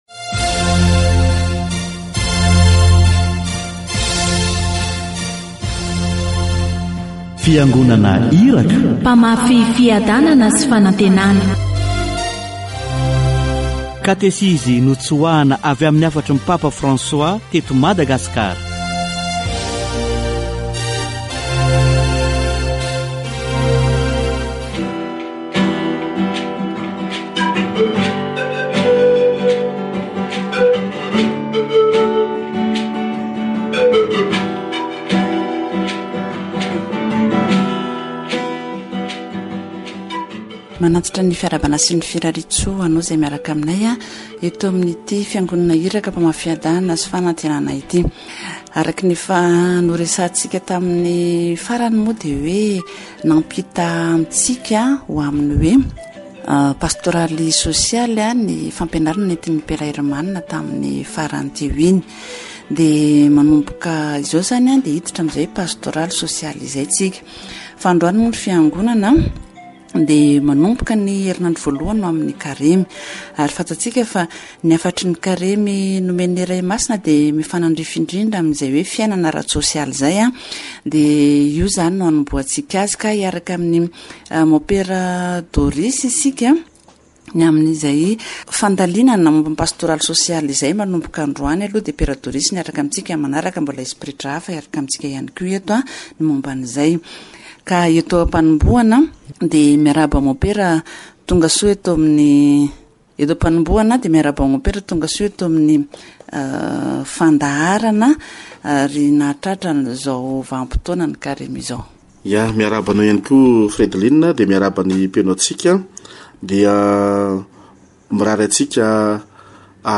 Lent, which is a time of change and repentance for all, leads us to the Passover of Jesus Christ dead and risen. Catechesis on "doing good"